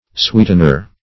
Sweetener \Sweet"en*er\, n.